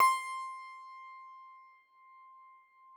53l-pno18-C4.wav